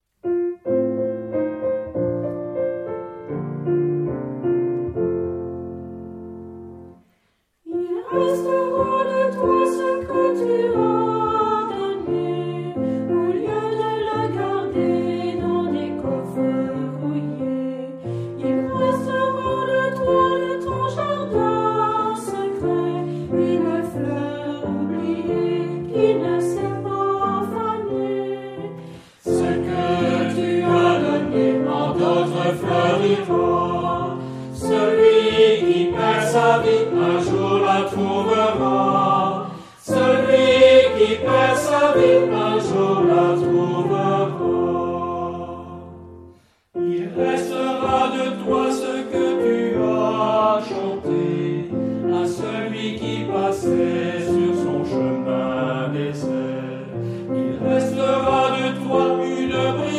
Genre-Style-Form: Canticle ; Sacred
Type of Choir: unisson
Instruments: Organ (1)
Tonality: F major